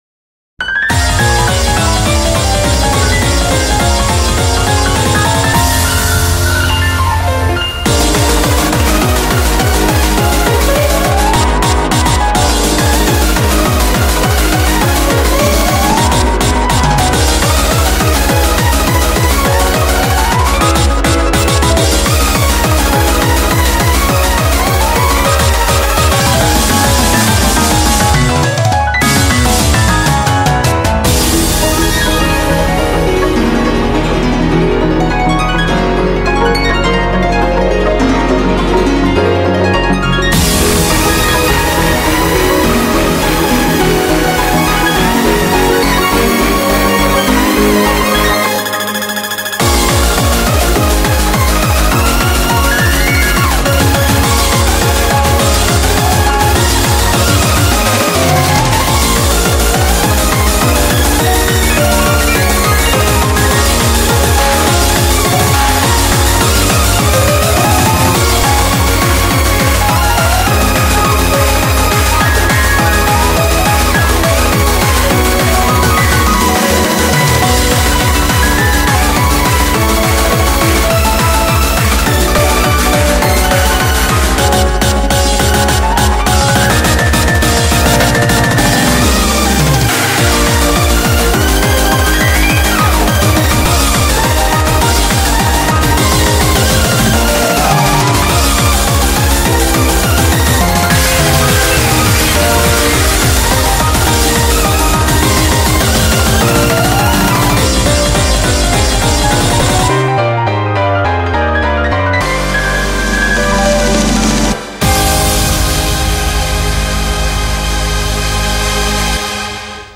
BPM207
MP3 QualityLine Out